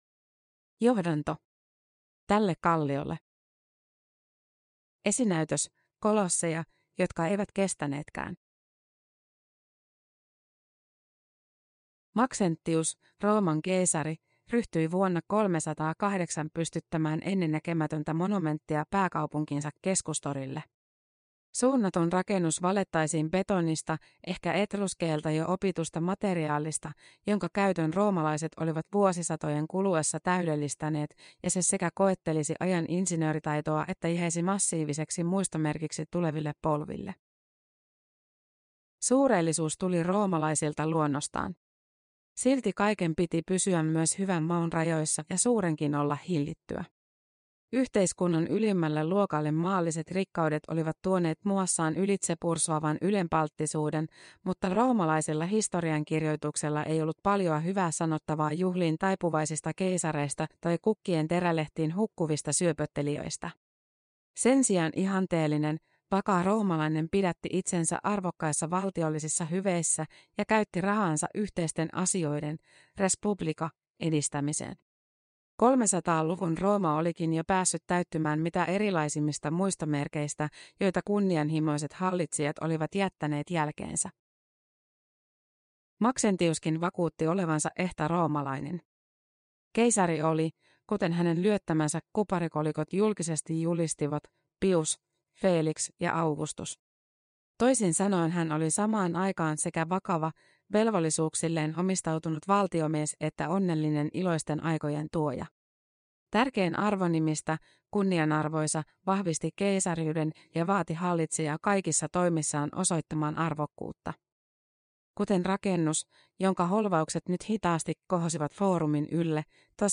Tälle kalliolle minä rakennan kirkkoni – Ljudbok – Laddas ner
Tekoäly A.I. Materin Ilonan lukema.
Uppläsare: Ilona A.I.Mater